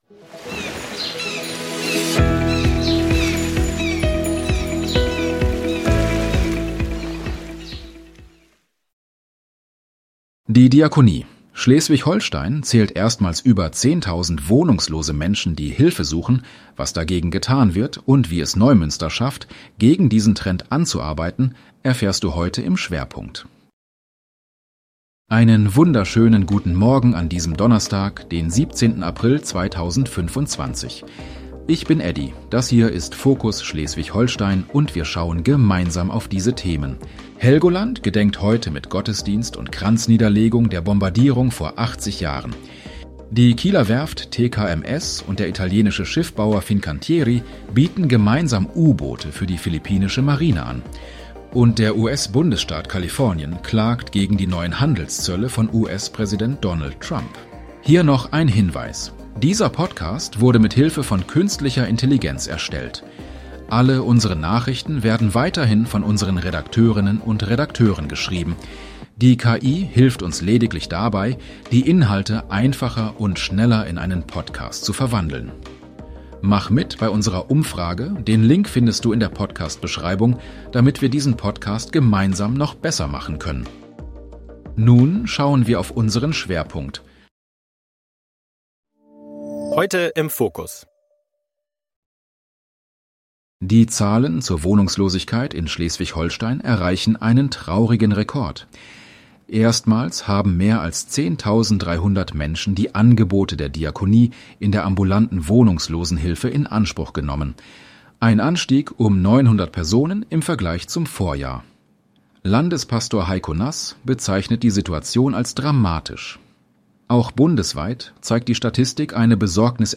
In unserem regionalen Nachrichten-Podcast bekommst Du ab 7